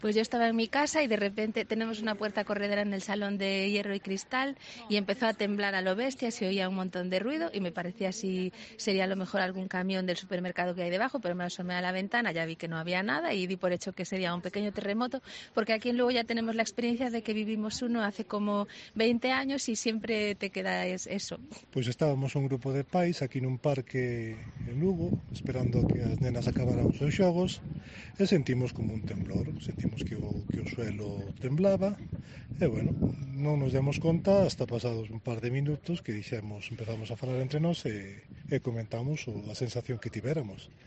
Vecinos de Lugo cuentan cómo han sentido el temblor registrado a primera hora de la tarde